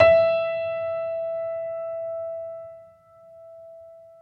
Vintage_Upright
e4.mp3